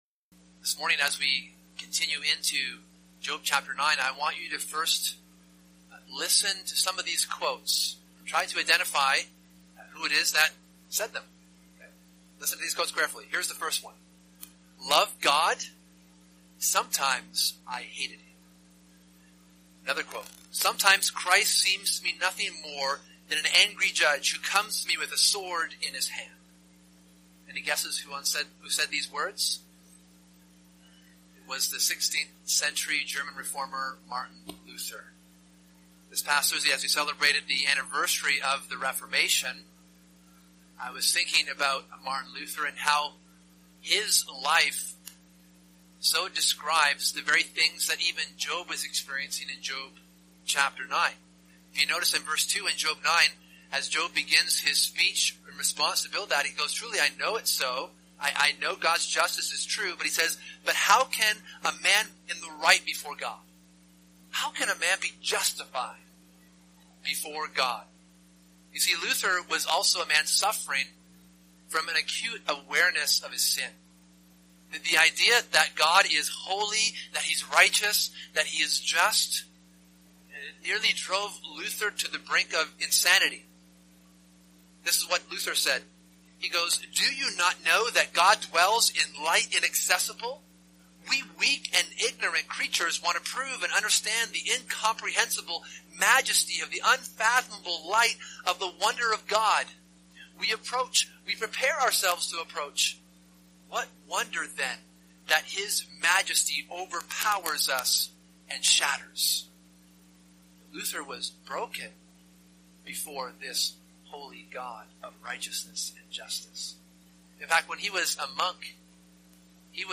Job Watch Listen Download Notes Download MP3 ** Note: The audio quality in this sermon is very poor.